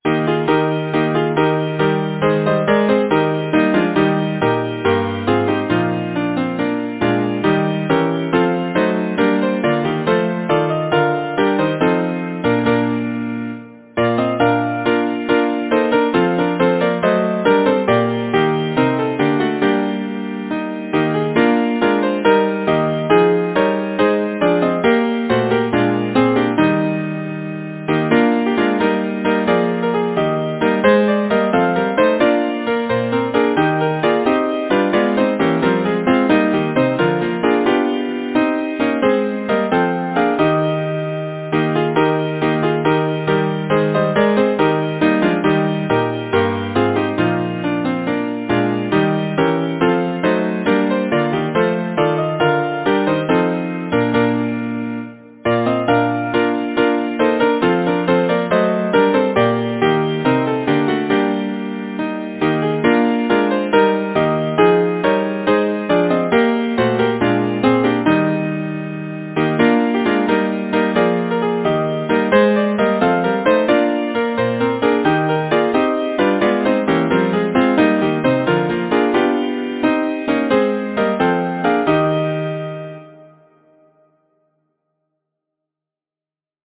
Title: Sing on, sweet Birds! Composer: Edward Bunnett Lyricist: Edward Oxenford Number of voices: 4vv Voicing: SATB Genre: Secular, Partsong
Language: English Instruments: A cappella